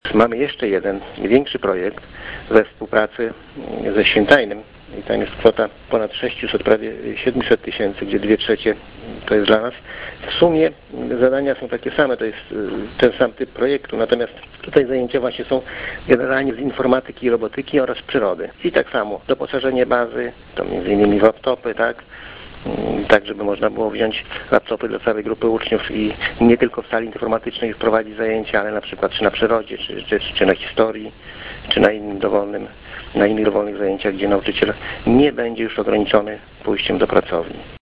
– Z projektów edukacyjnych skorzysta również 90 rodziców- mówi Wacław Olszewski, burmistrz Olecka.